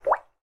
mixkit-water-bubble-1317.ogg